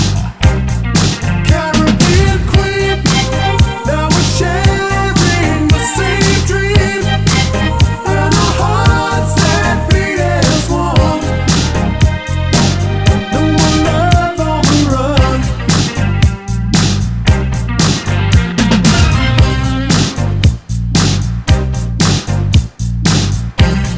Two Semitones Down Pop (1980s) 4:01 Buy £1.50